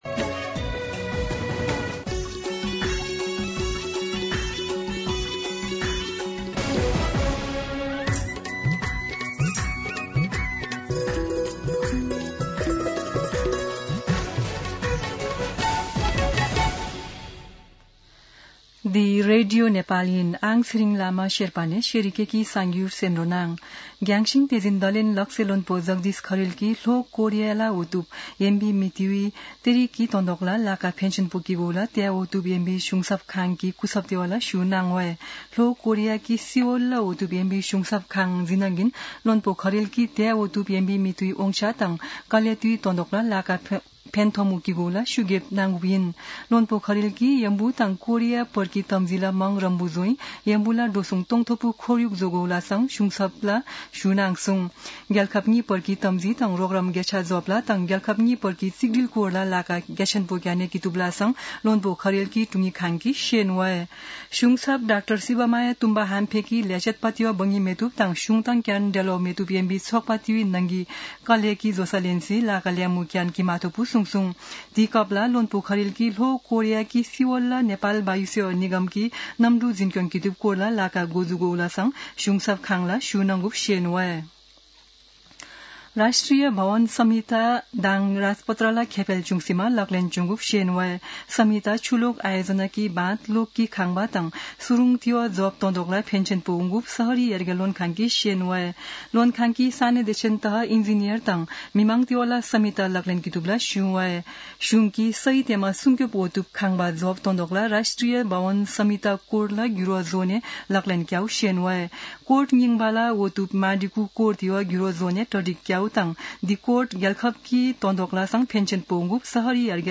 शेर्पा भाषाको समाचार : २८ कार्तिक , २०८२
Sherpa-News-7-28.mp3